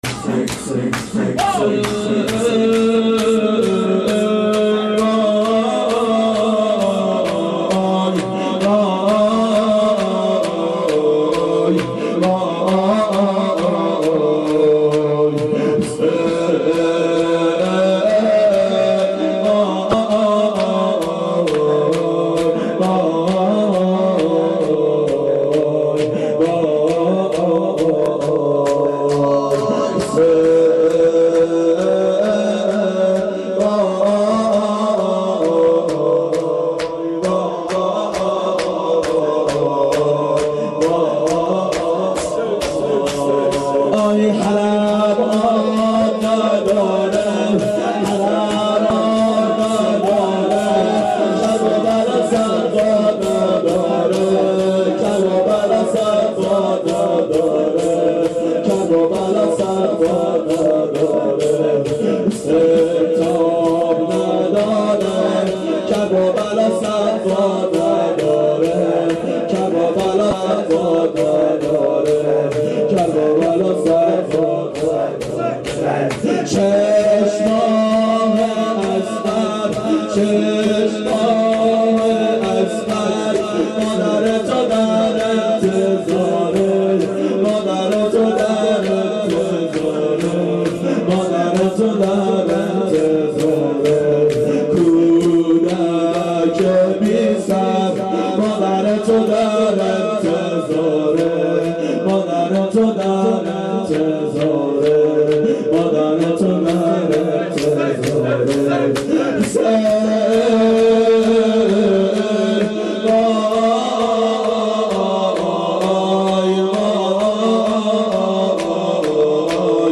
شب عاشورا 1389 هیئت عاشقان اباالفضل علیه السلام